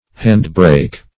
Search Result for " handbrake" : The Collaborative International Dictionary of English v.0.48: handbrake \hand"brake`\, hand-brake \hand"-brake`\n. a brake operated by hand, used to stop a vehicle or keep it stationary; it usually operates by a mechanical linkage.